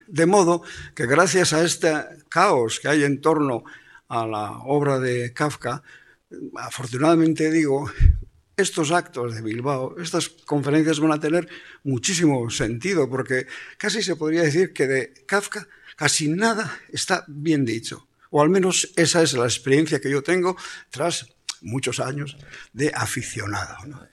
En la propia presentación, Bernardo Atxaga ha definido las obras de Kafka como una obra malinterpretada y mal entendida.